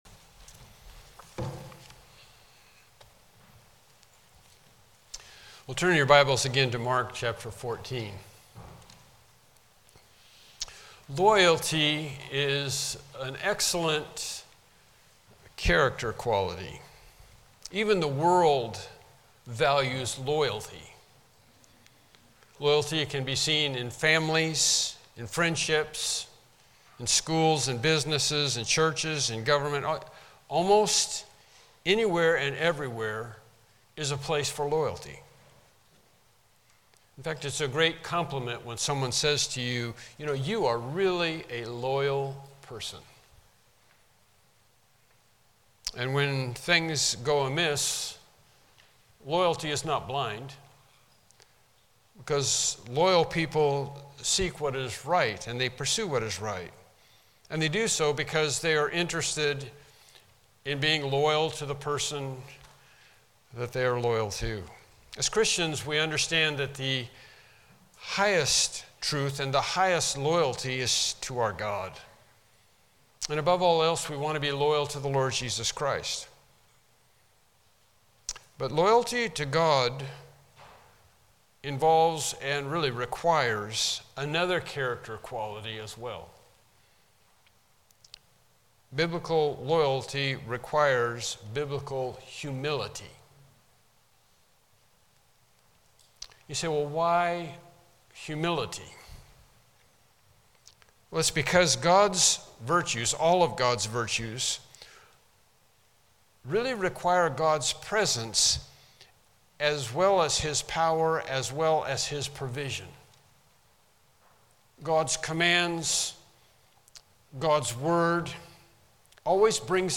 Mark 7:27-31 Service Type: Morning Worship Service « Lesson 9 The Trumpets of the Fifth and Sixth Angels Why the Tribulation